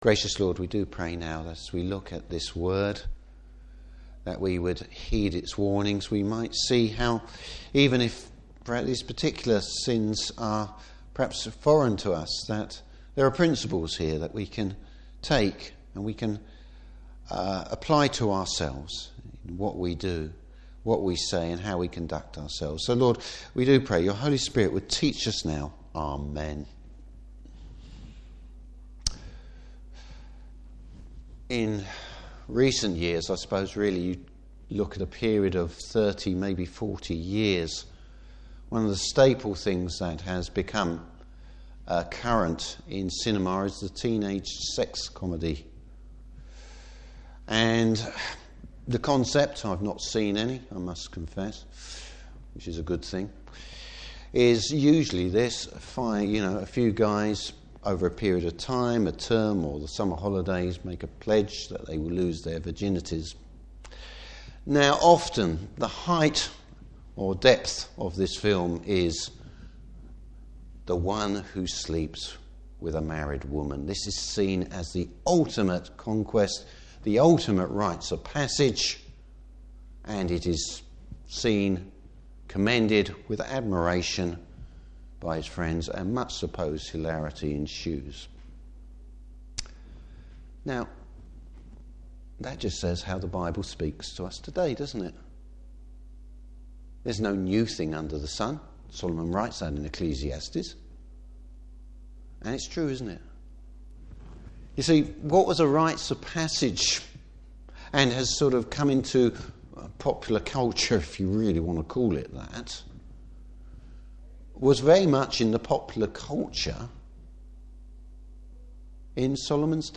Service Type: Morning Service The danger of sexual sin!